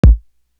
Living The Life Kick.wav